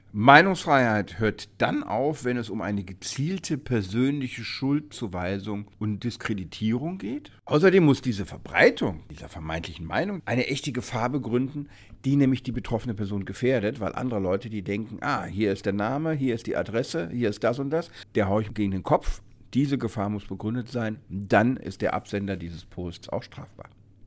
O-Ton: Abtreibungsgegner wegen gefährdender Verbreitung personenbezogener Daten verurteilt – Vorabs Medienproduktion